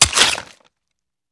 swipe2.wav